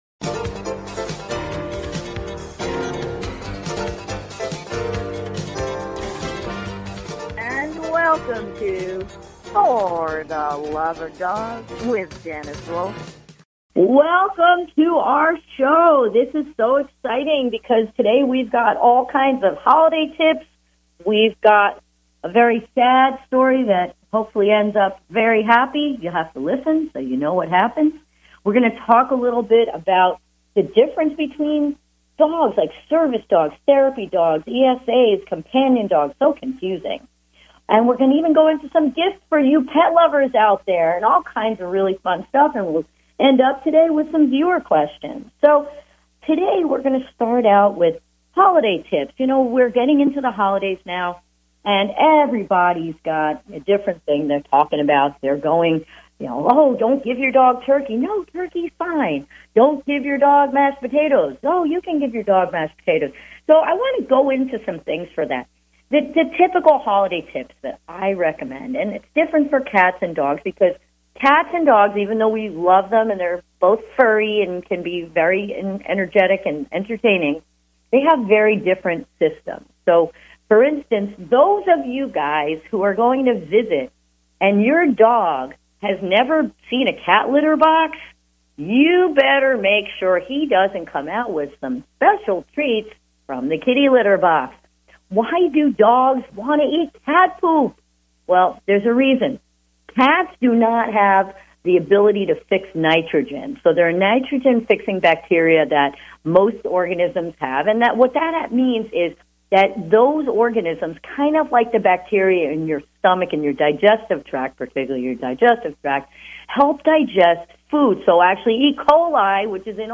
Talk Show Episode, Audio Podcast, Pet Savant Unleashed and Training for Pet Excellence! and other pet tips.